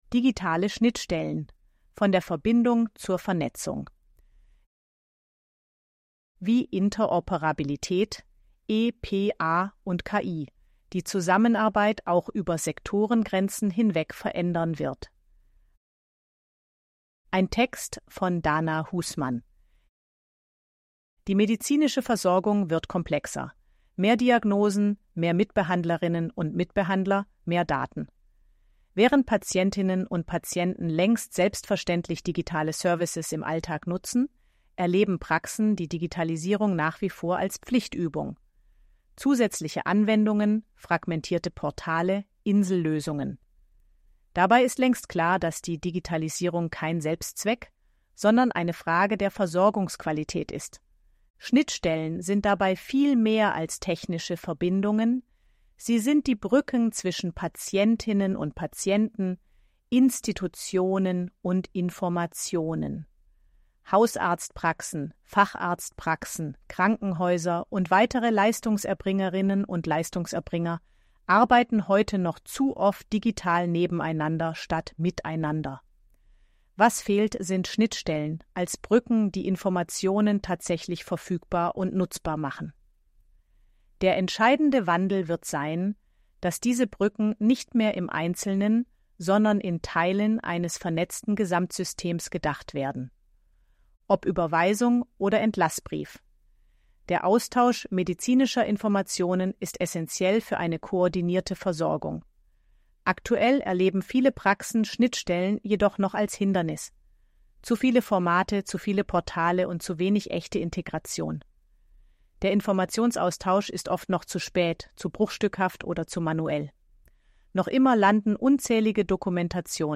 ElevenLabs_KVN261_12_Leonie.mp3